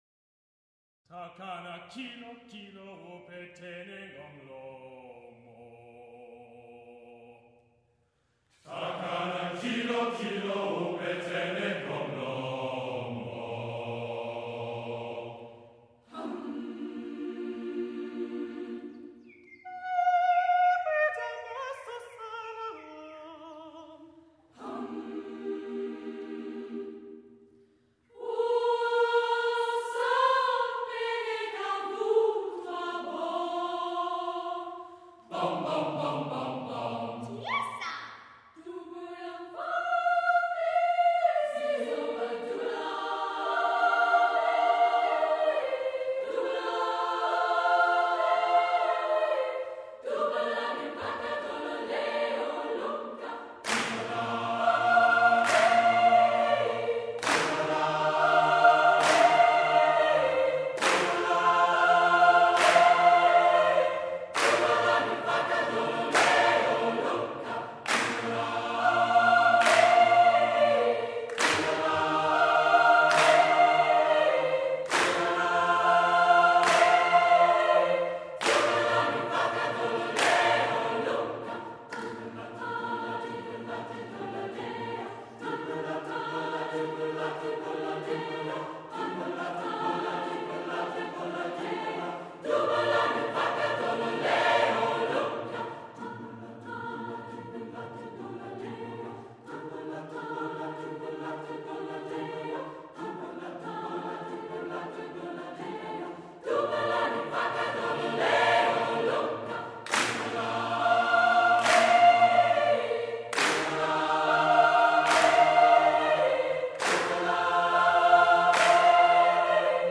Dubula Xhosa folk song
Recording of my old choir NYC singing it, before my time, but with my darling husband. It’s got boys but it’s very singalongable!